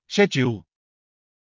読み上げた単語は”schedule（スケジュール）”、①がイギリス英語、②がアメリカ英語です。
schedule/ˈʃedʒ.uːl//ˈskedʒ.uːl/
①BE-schedule.mp3